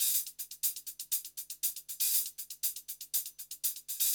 HIHAT LO10.wav